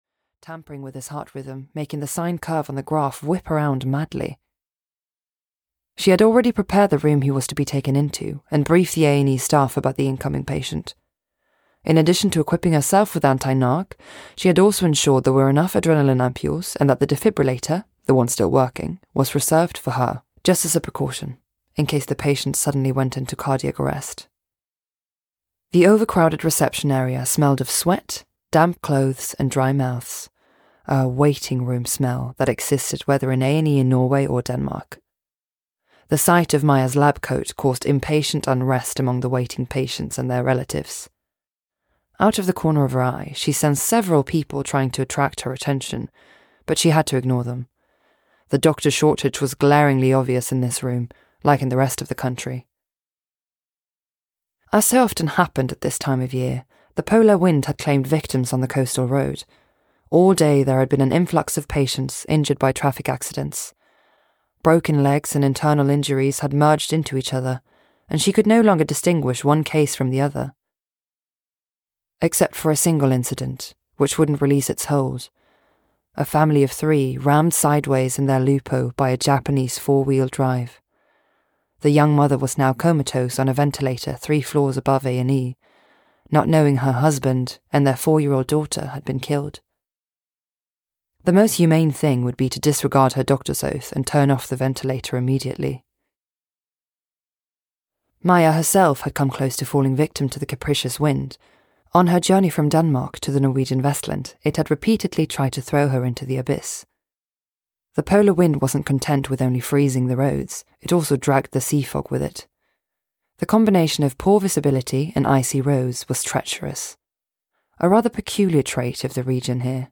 Before the Storm (EN) audiokniha
Ukázka z knihy